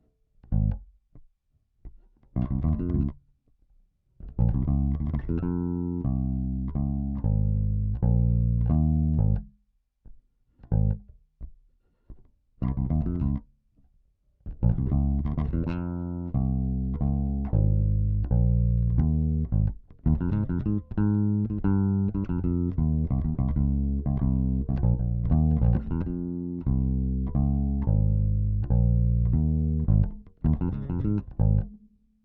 ベースラインでこの曲なーんだ